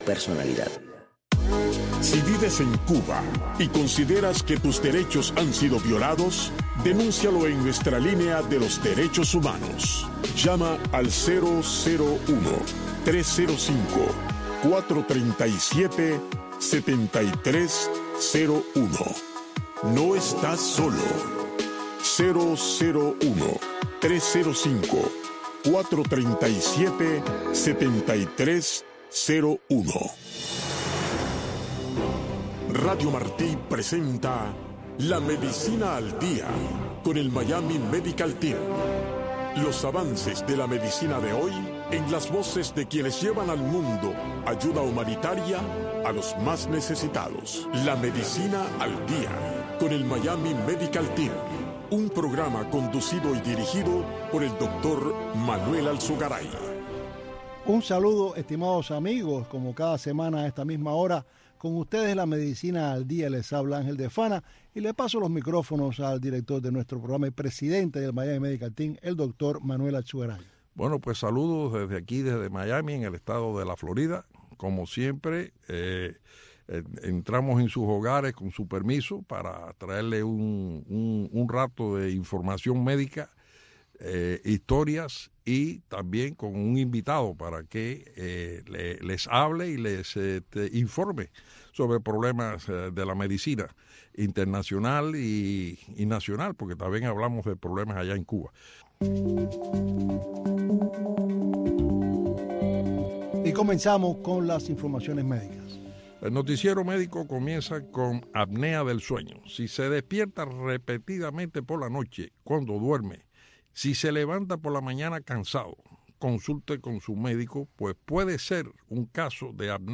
Un programa concebido para que conozcas los avances de la medicina en el mundo de hoy, en la voz de consagrados galenos y diversos profesionales del mundo de la medicina.